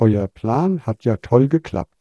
sample04-wavegrad.wav